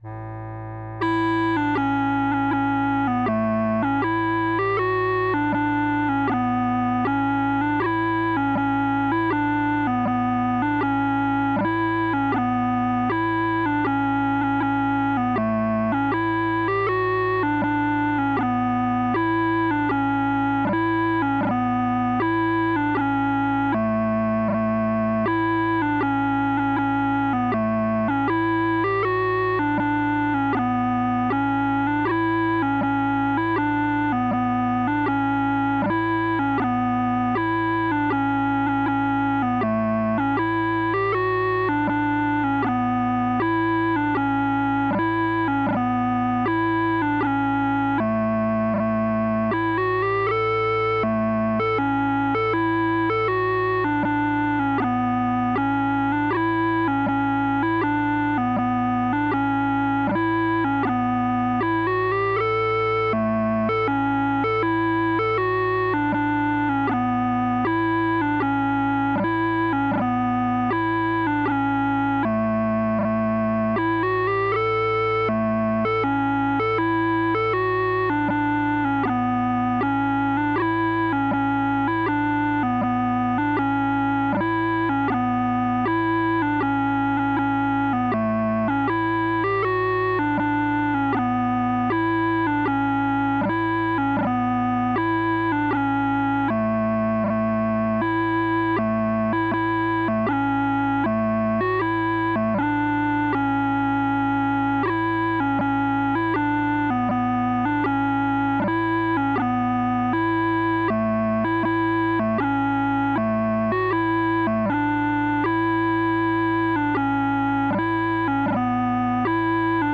Bagpipes and Drums